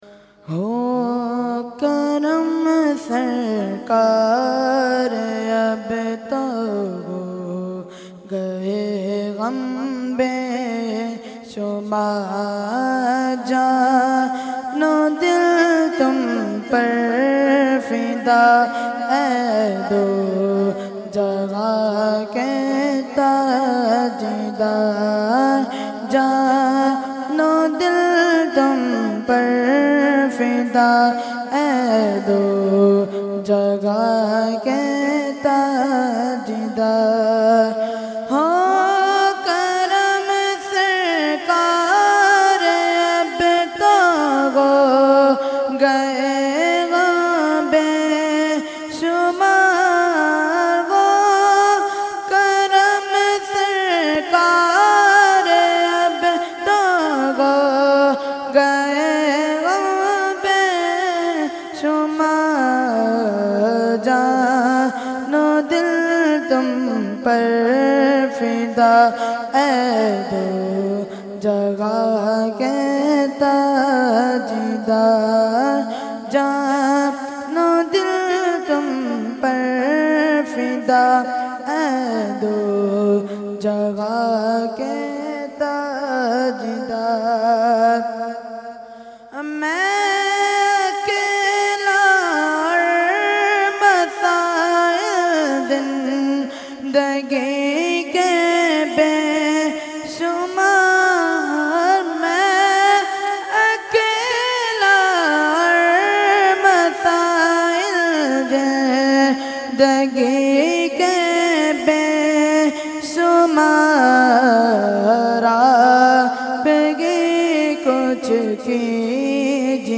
held on 25,26,27 August 2022 at Dargah Alia Ashrafia Ashrafabad Firdous Colony Gulbahar Karachi.
Category : Naat | Language : UrduEvent : Urs Makhdoome Samnani 2022